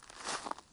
Rock Foot Step 4.wav